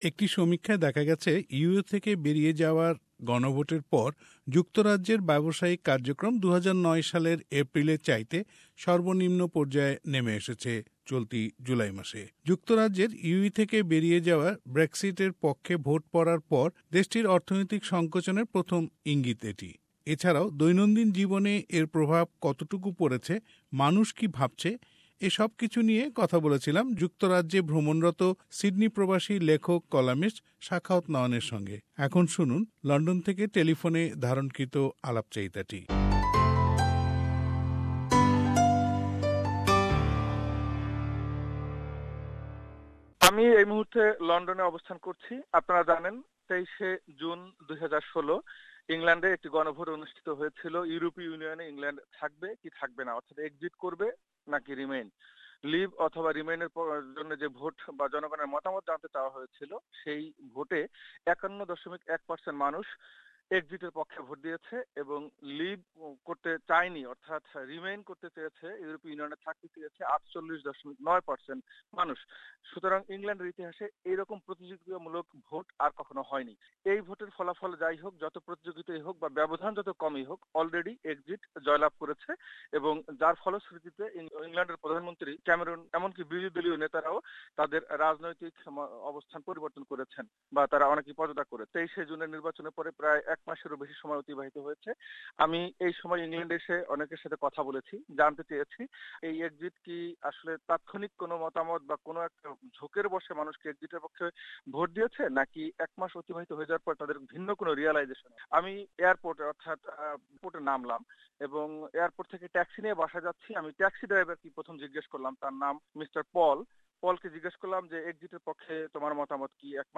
How Britain experiencing Brexit : Interview